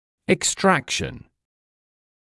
[ɪk’strækʃn][ик’стрэкшн]удаление (о зубах)